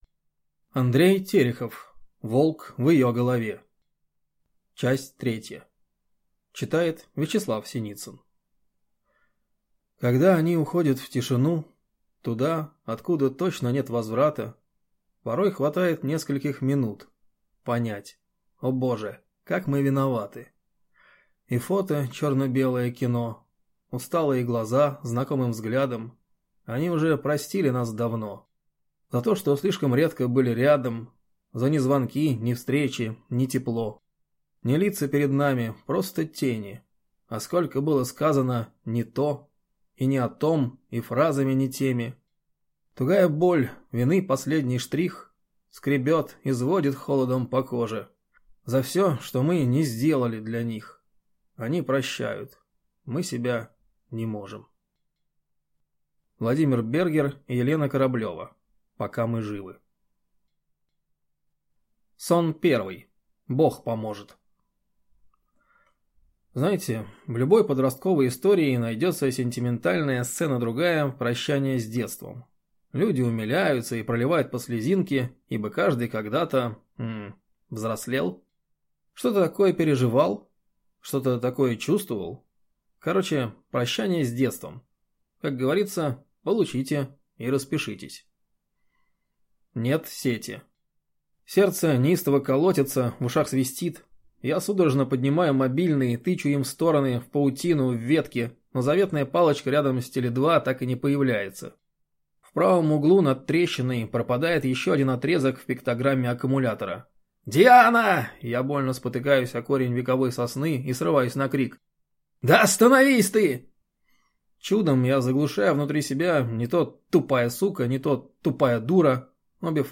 Аудиокнига Волк в ее голове. Часть III | Библиотека аудиокниг